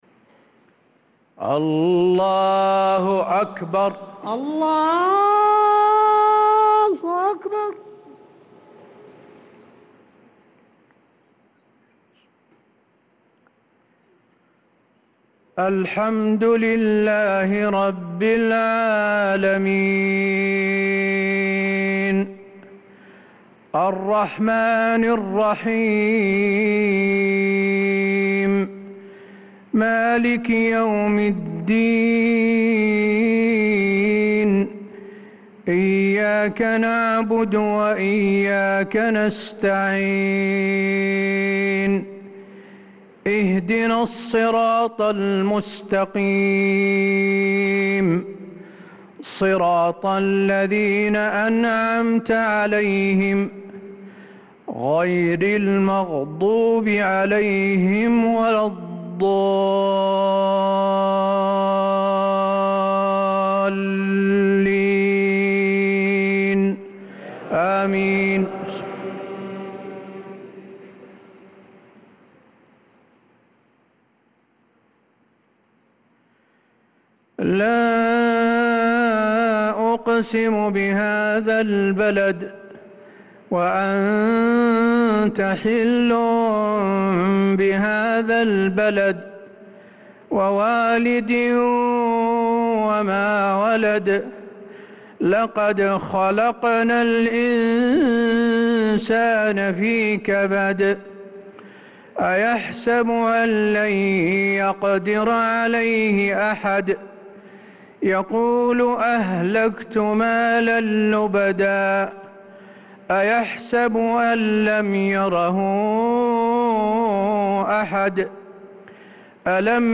صلاة العشاء للقارئ حسين آل الشيخ 24 محرم 1446 هـ
تِلَاوَات الْحَرَمَيْن .